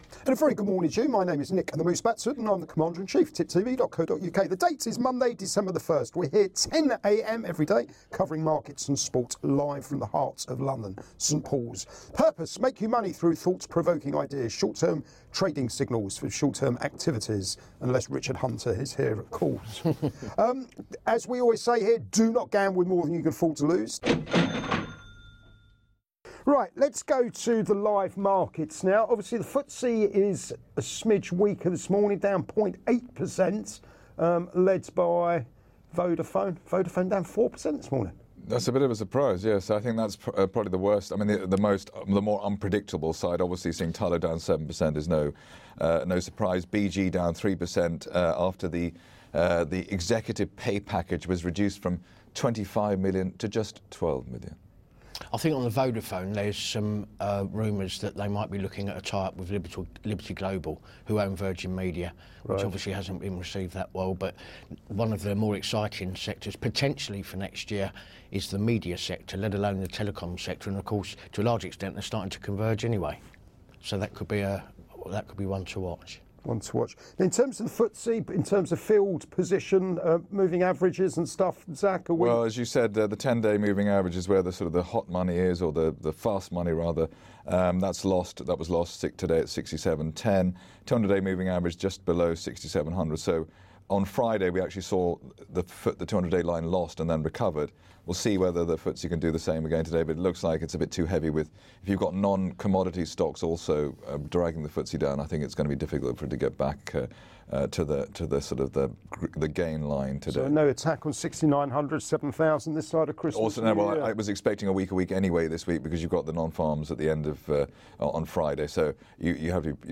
Live Market Round-Up & Soapbox thoughts